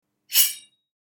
Download Knife sound effect for free.
Knife